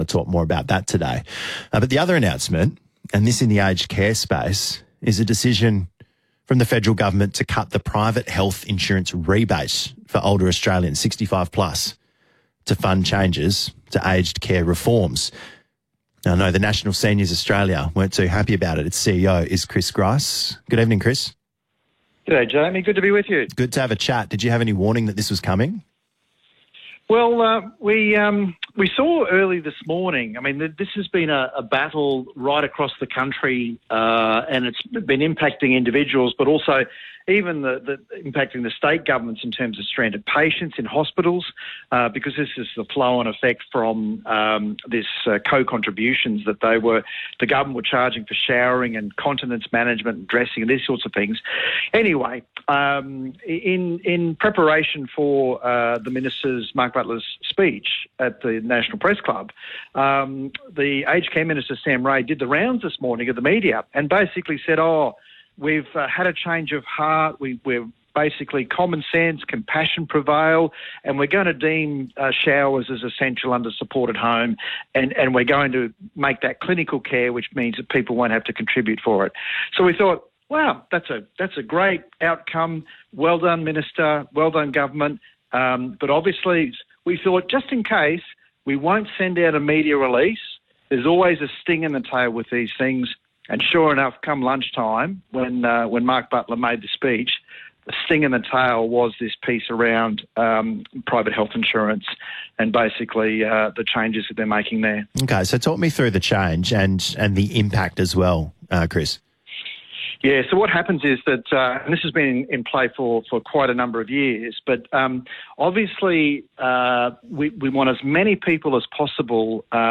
In an interview with 6PR Perth